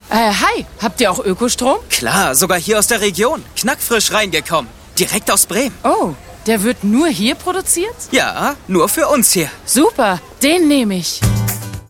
dunkel, sonor, souverän, markant, sehr variabel
Mittel minus (25-45)
Commercial (Werbung)